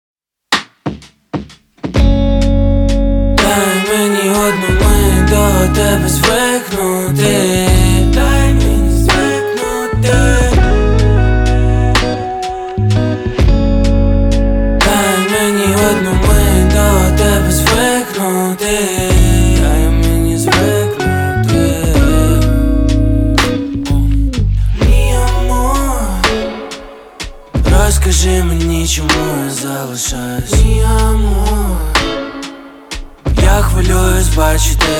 Жанр: Рэп и хип-хоп / Украинские